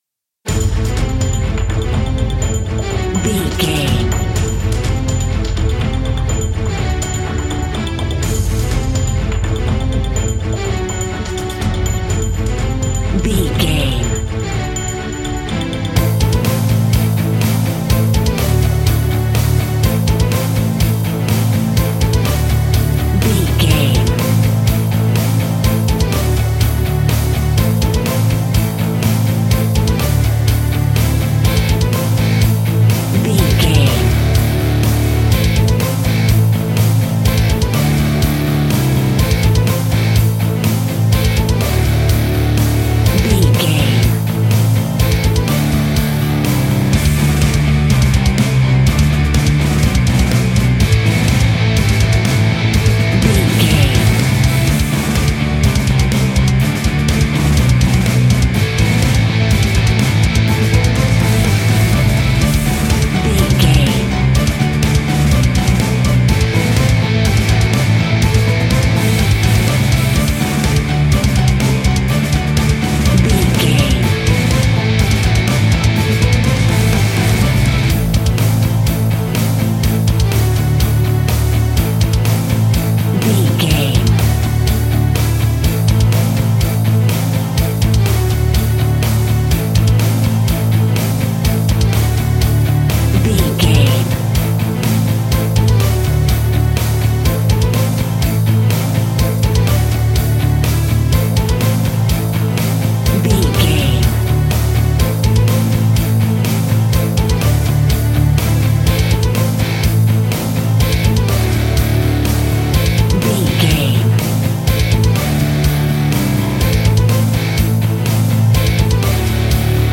Epic / Action
Fast paced
Aeolian/Minor
Fast
hard rock
Heavy Metal Guitars
Metal Drums
Heavy Bass Guitars